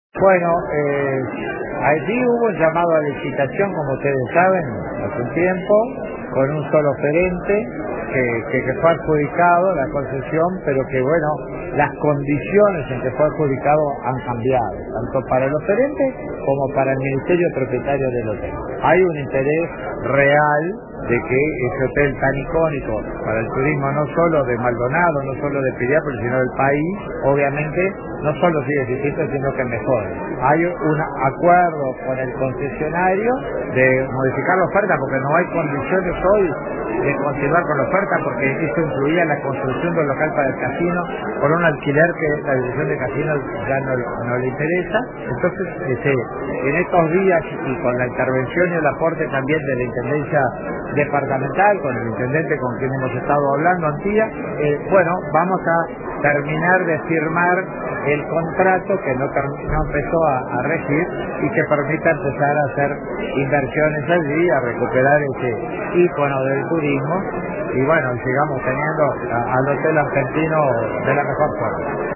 En diálogo con RADIO RBC, Viera explicó que la oferta original incluía la construcción de un local para el casino, por un alquiler que la Dirección de Casinos ya no está dispuesta a pagar.